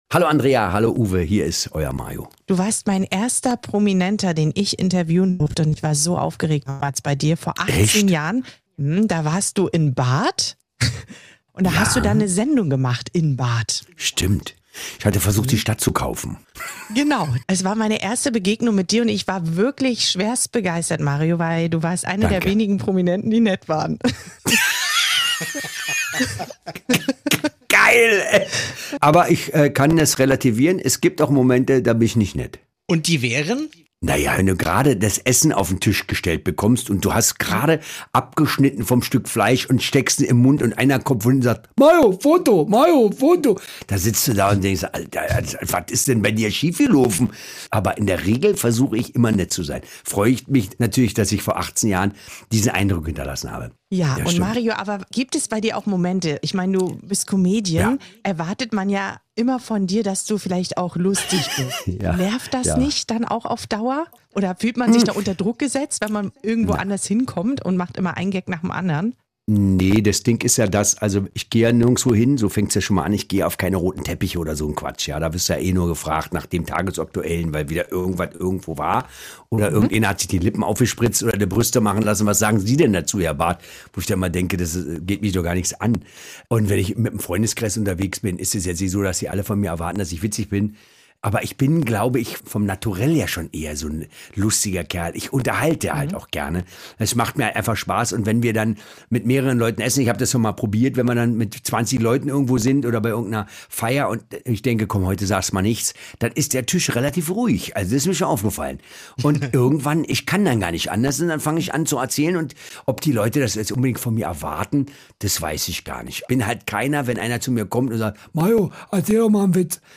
Im Guten Morgen hat er sich aus dem Homeoffice zugeschaltet und über sein Jahr gesprochen.